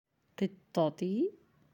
(qittati)